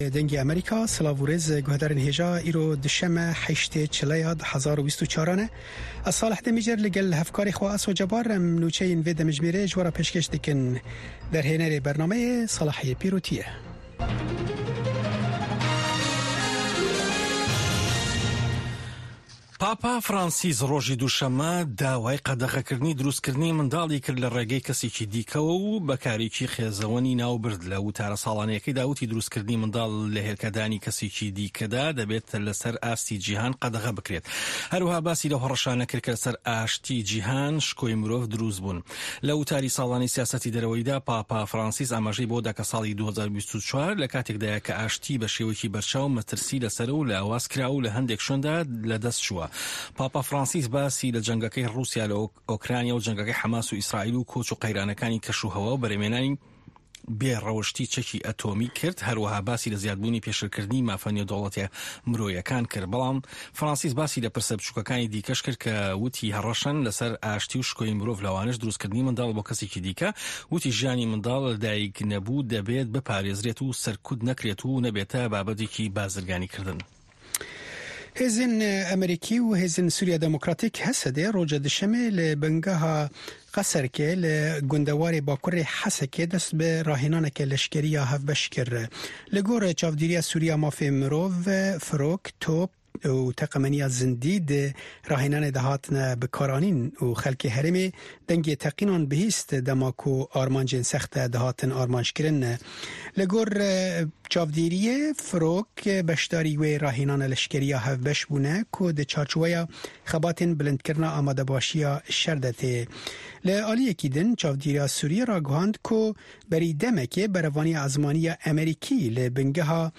Nûçeyên 1’ê paşnîvro
Nûçeyên Cîhanê ji Dengê Amerîka